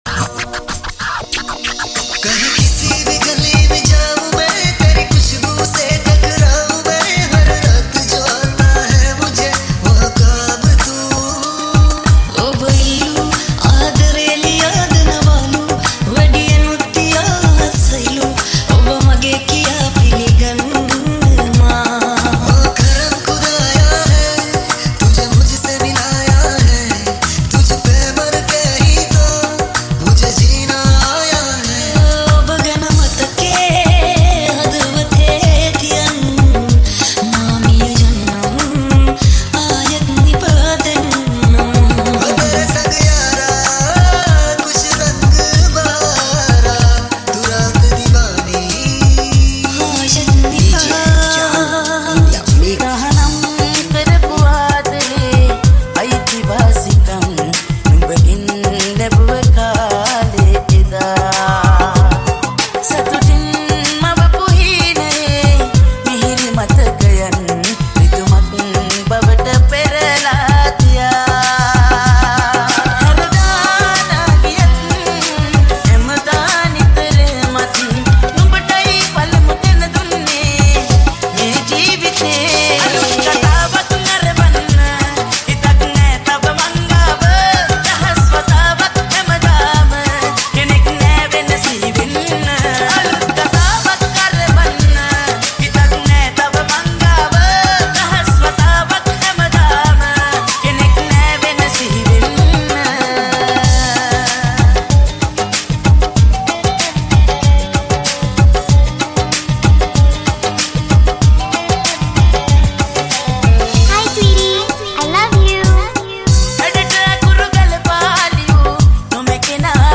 High quality Sri Lankan remix MP3 (12.9).
remix